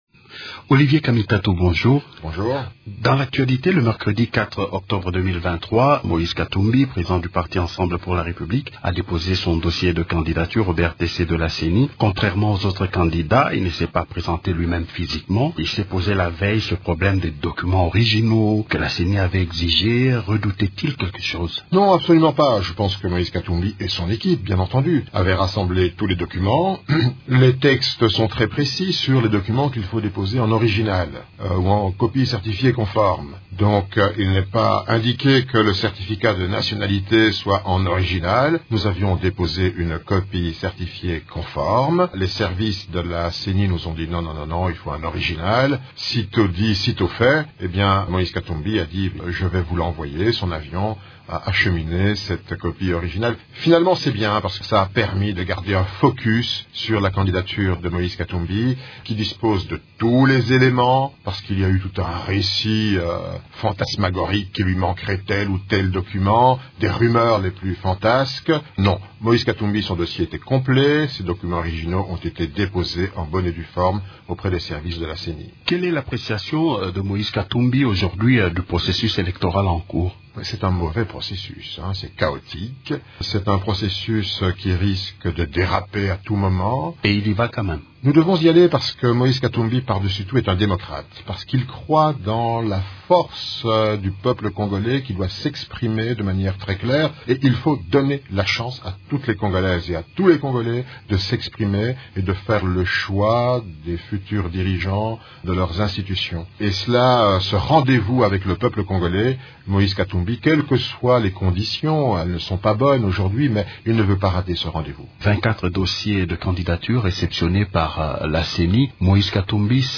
Invité de Radio Okapi, il note que ce processus n’a pas de bases solides et contient des risques de dérapage.